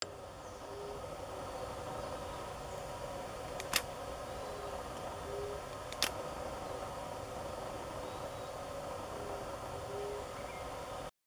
Yungas Dove (Leptotila megalura)
Life Stage: Adult
Location or protected area: Parque Nacional Calilegua
Condition: Wild
Certainty: Recorded vocal